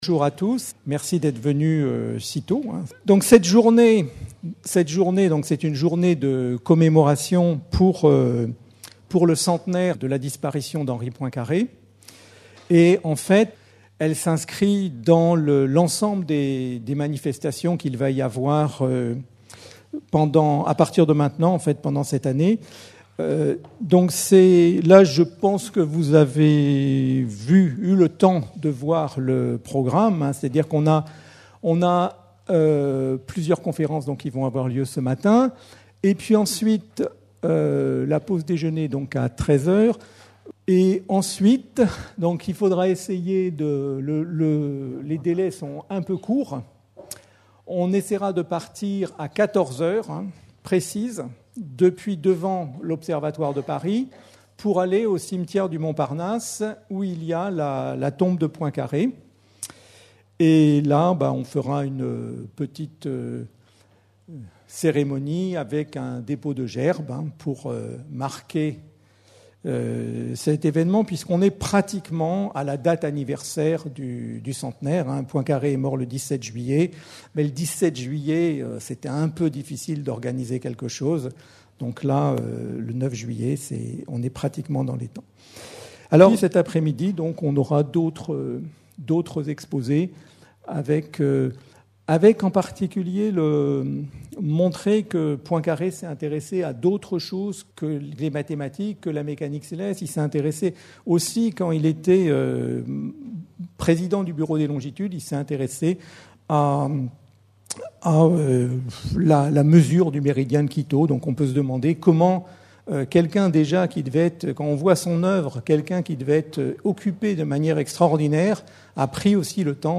Journée de commémoration du centenaire de la disparition de Henri Poincaré (29 avril 1854 - 17 juillet 1912), organisée à l'Institut d'Astrophysique de Paris le 9 Juillet 2012. Cette journée veut marquer la contribution du scientifique en Mécanique Céleste, Astronomie, Géodésie, et Géophysique.